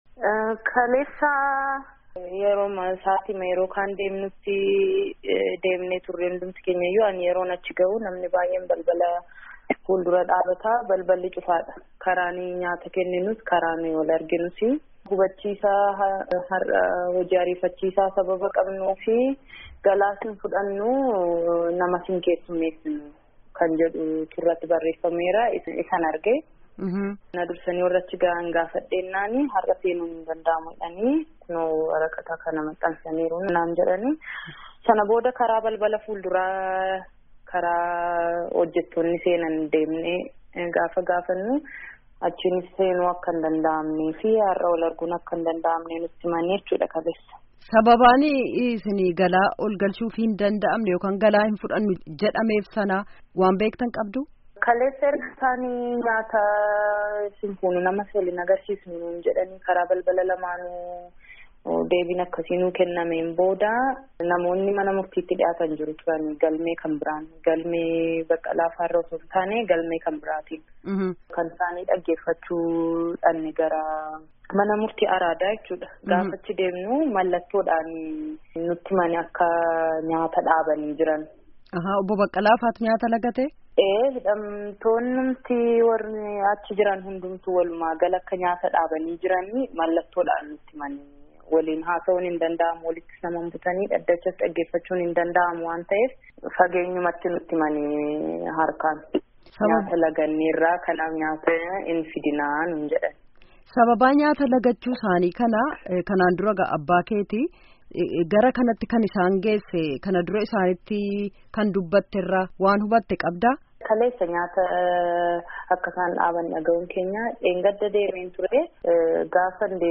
Gaafii fi Deebii